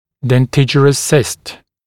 [den’tɪʤərəs sɪst][дэн’тиджэрэс сист]фолликулярная (зубосодержащая) киста